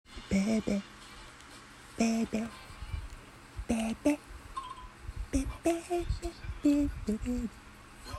아래는 제가 노래를 따라부른 예시입니다